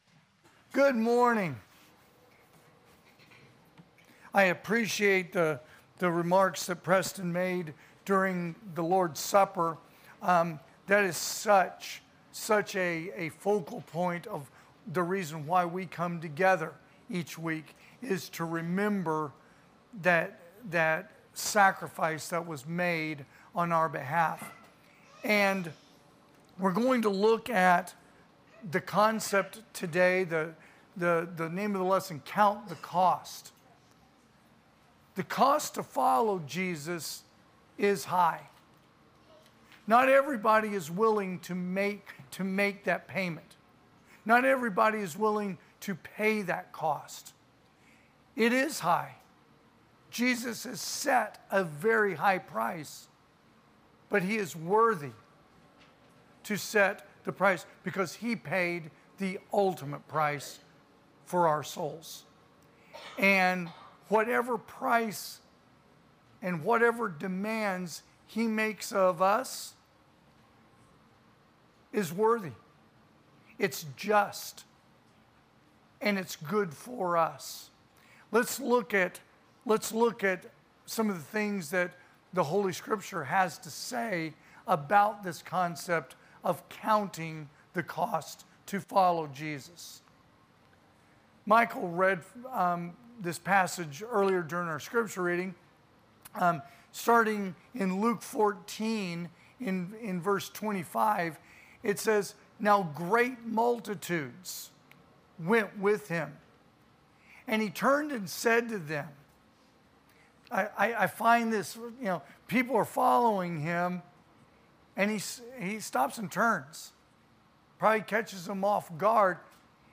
2025 (AM Worship) "Count The Costs"